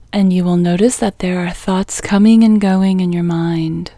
LOCATE Short IN English Female 3